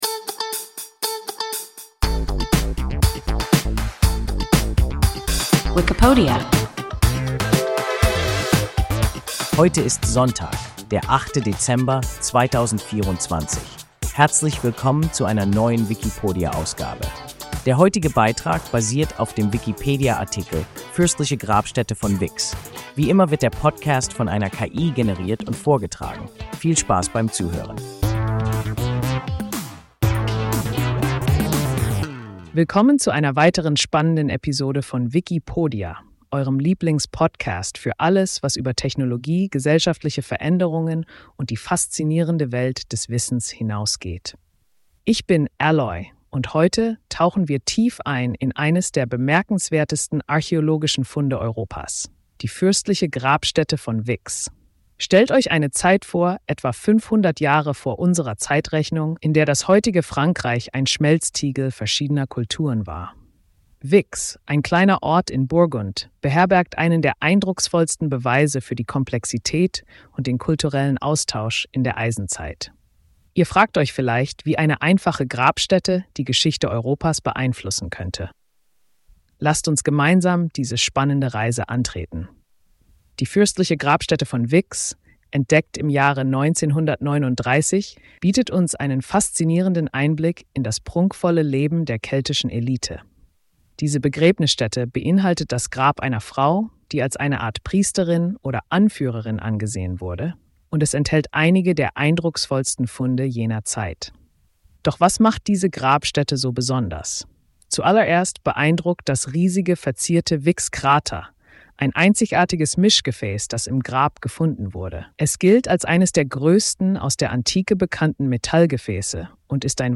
Fürstliche Grabstätte von Vix – WIKIPODIA – ein KI Podcast